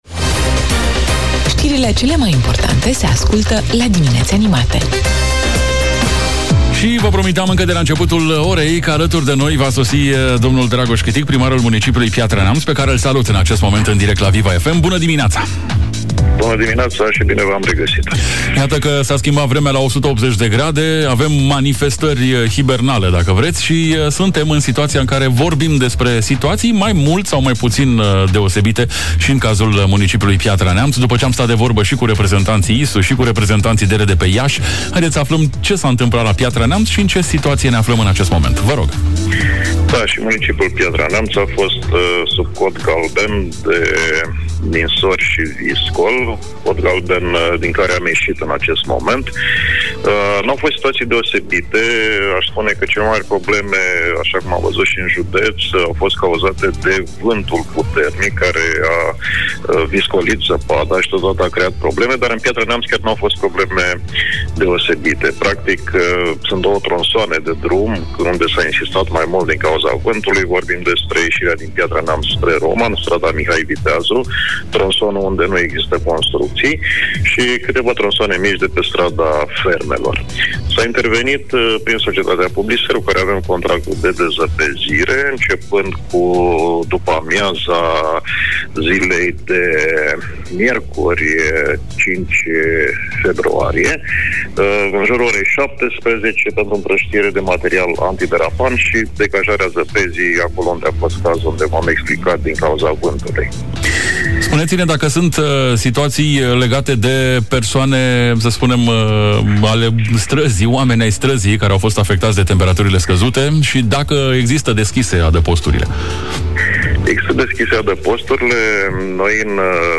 Vremea ultimelor 24 de ore s-a resimțit și la Piatra Neamț. Amănunte ne-a oferit în direct la Viva FM Neamț domnul Dragoș Chitic, primarul municipiului.